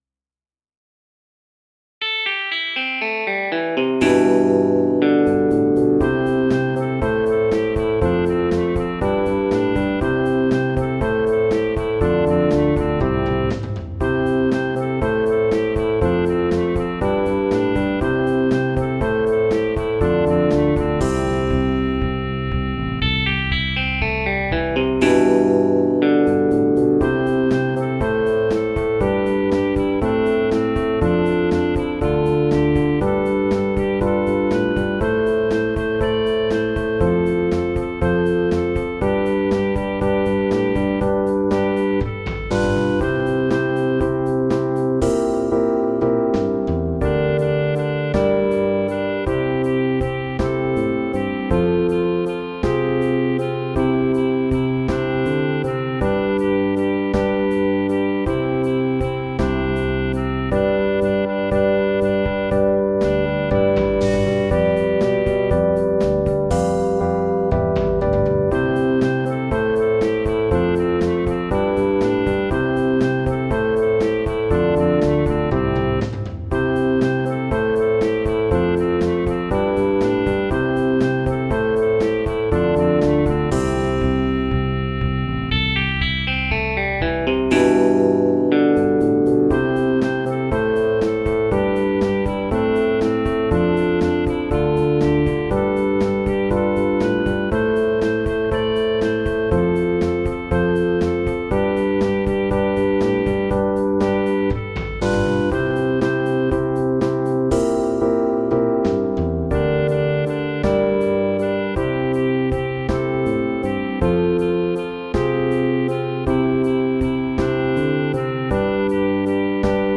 KATEGORIA I – chóry dziecięce do lat 10
Pandy podkład